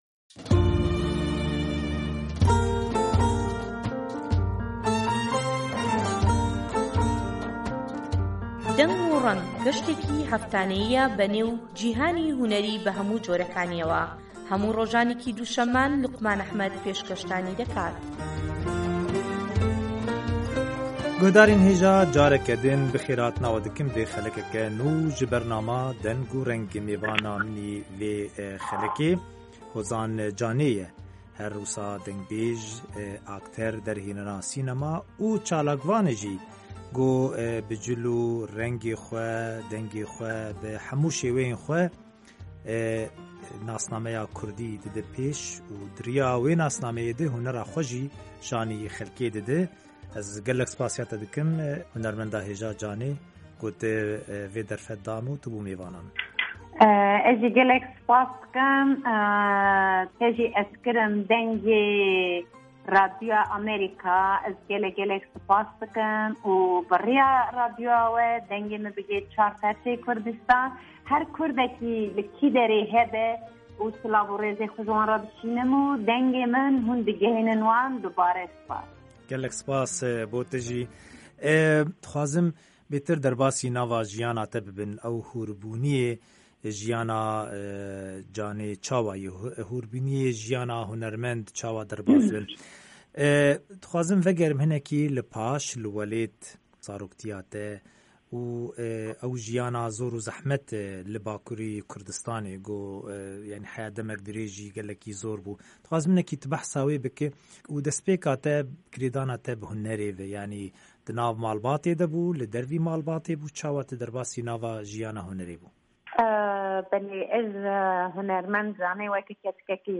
Hevpeyvîn_Hozan_Canê_LA